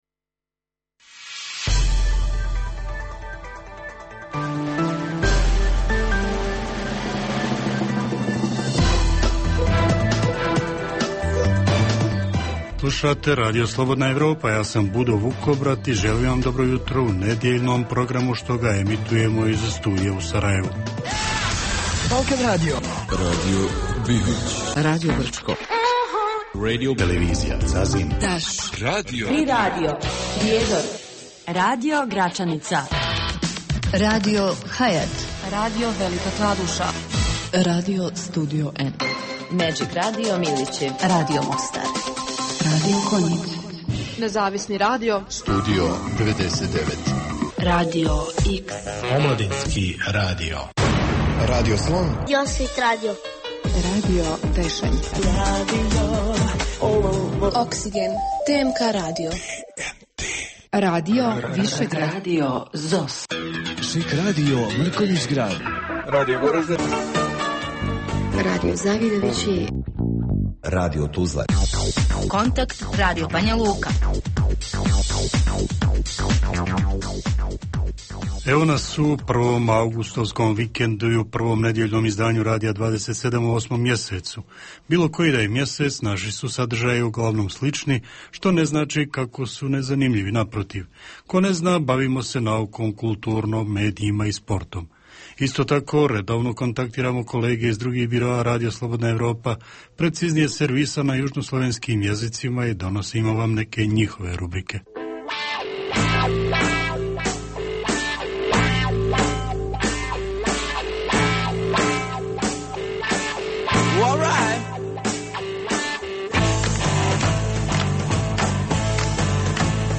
Jutarnji program namijenjen slušaocima u Bosni i Hercegovini. Sadrži intervju, te novosti iz svijeta nauke, medicine, visokih tehnologija, sporta, filma i muzike.